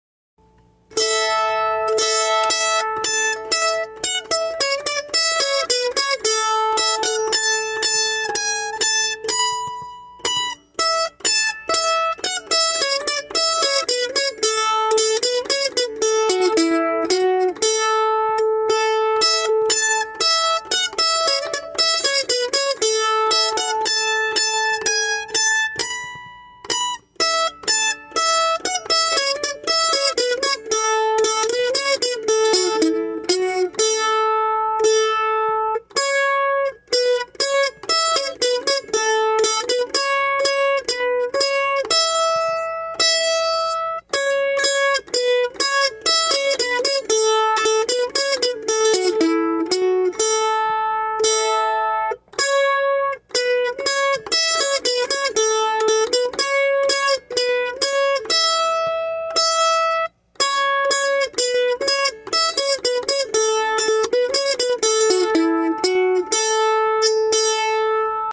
Key: A
Form: Reel
Played slowly for learning